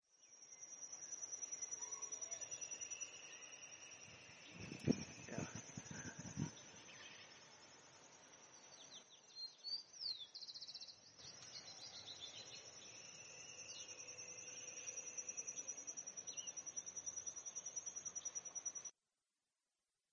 Red-and-white Crake (Rufirallus leucopyrrhus)
Life Stage: Adult
Location or protected area: Reserva Ecológica Costanera Sur (RECS)
Condition: Wild
Certainty: Recorded vocal